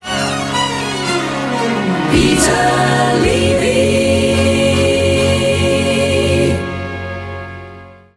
I loved its cascading strings and the sheer presence.